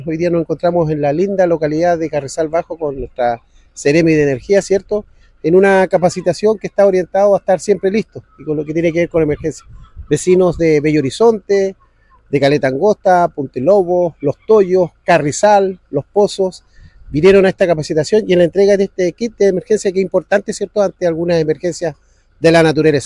El alcalde de Huasco, Genaro Briceño, relevó el impacto territorial de la iniciativa “Hoy día nos encontramos en la linda localidad de Carrizal Bajo con nuestra seremi de Energía, en una capacitación que está orientado a estar Siempre Listos, con lo que tiene que ver con emergencia. Vecinos de Bello Horizonte, Caleta Angosta, Punta de Lobos, Los Toyos, Carrizal Bajo y Los Pozos, vinieron a esta capacitación y a la entrega de este kit de emergencia, que es importante ante algunas emergencias de la naturaleza”, afirmó.